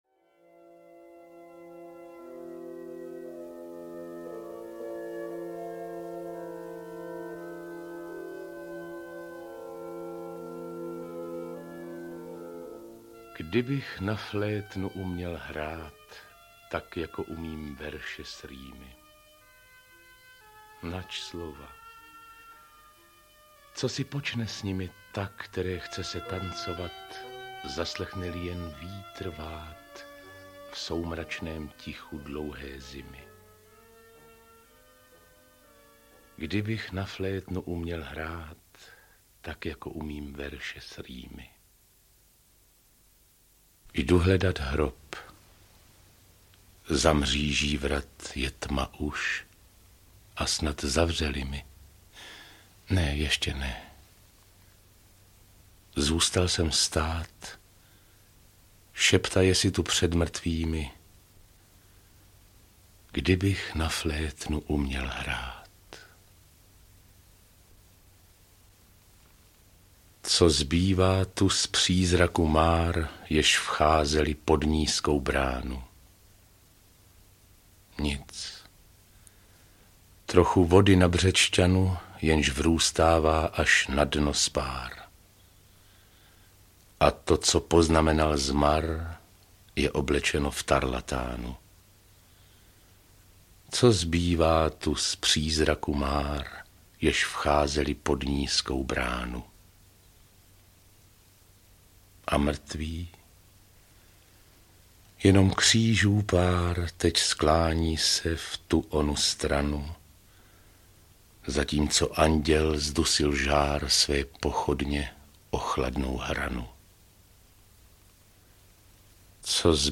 Mozart v Praze, Mozartiana audiokniha
Ukázka z knihy
• InterpretRadovan Lukavský, Václav Voska